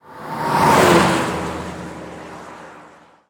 car5.ogg